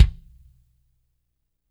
-DRY NS 5 -L.wav